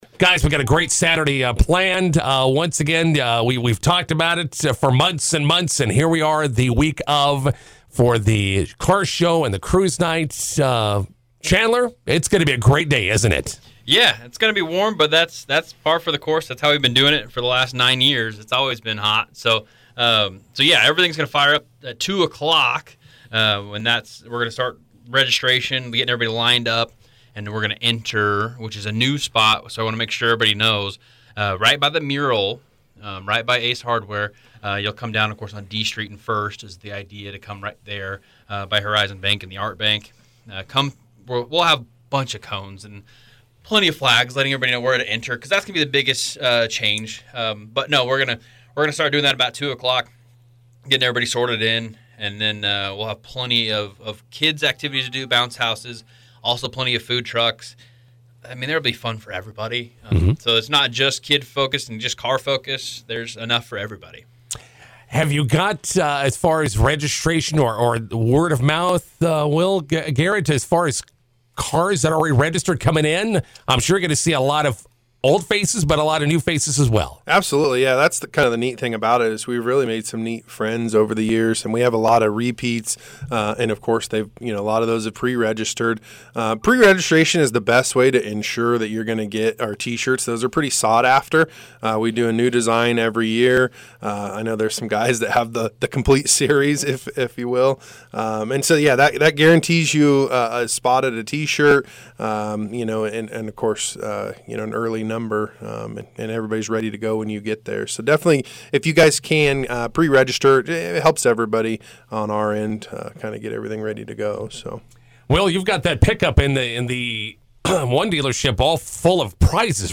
INTERVIEW: Wagner Ford-Toyota hosting their ninth annual Car Show and Cruise Night on Saturday.